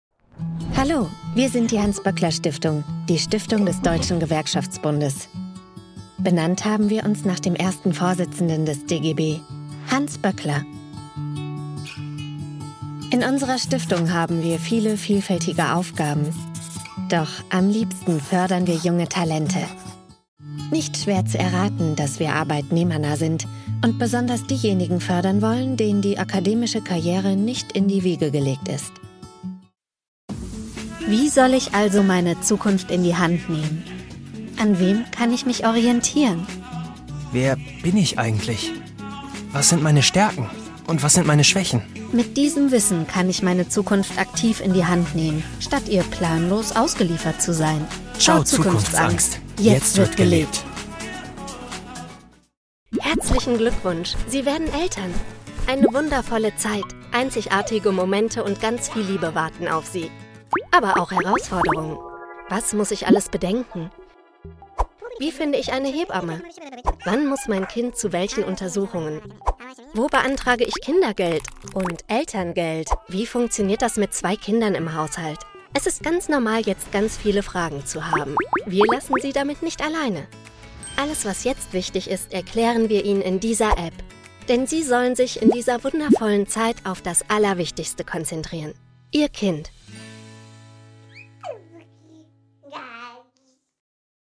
Female
Bright, Character, Cheeky, Confident, Cool, Corporate, Friendly, Natural, Soft, Warm, Versatile, Young, Approachable, Assured, Authoritative, Bubbly, Conversational, Energetic, Engaging, Funny, Posh, Reassuring, Sarcastic, Smooth, Streetwise, Upbeat
My fresh and young, yet warm voice is often described as special and trustworthy at the same time.
REEL CORPORATE IMAGE MOVIE DE.mp3
Microphone: Neumann TLM49